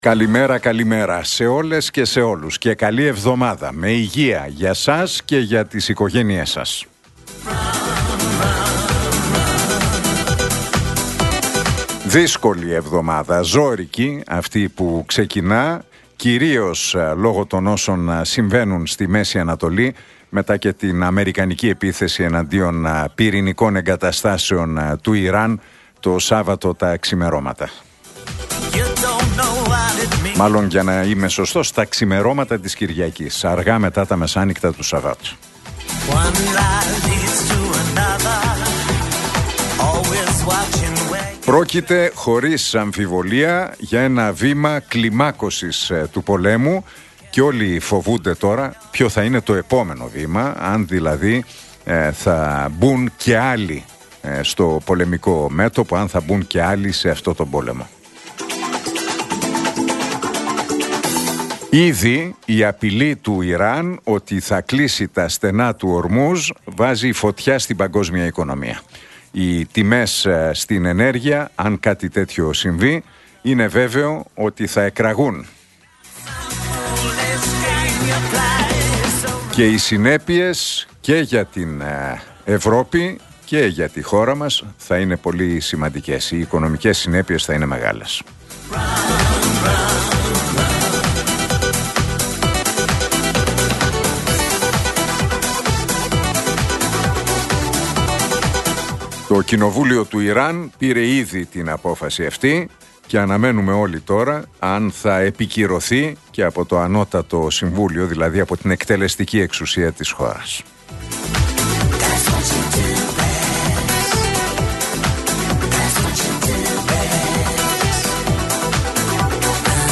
Ακούστε το σχόλιο του Νίκου Χατζηνικολάου στον ραδιοφωνικό σταθμό Realfm 97,8, την Δευτέρα 23 Ιουνίου 2025.